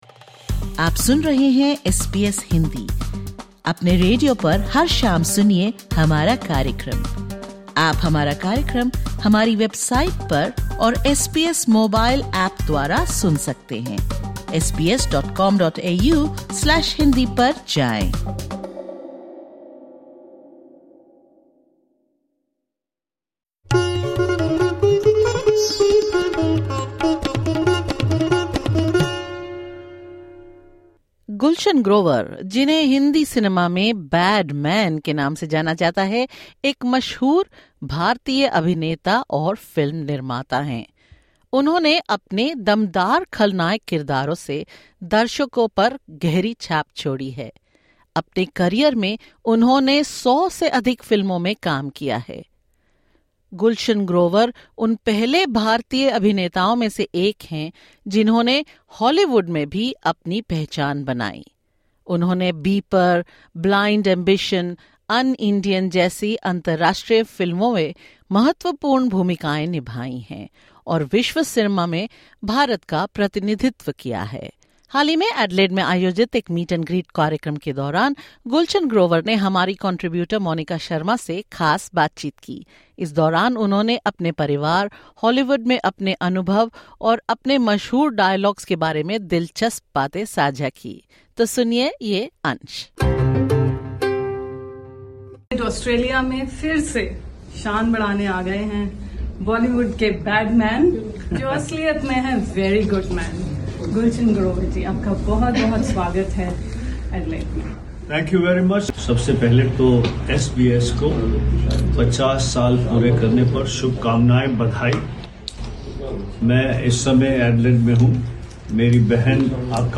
During his recent visit to Adelaide, Gulshan Grover, famous for his 'bad man' roles, shares a personal side in a conversation with SBS Hindi. He discusses his Hollywood journey and the growing global recognition of Indian cinema at events like Cannes and the Met Gala.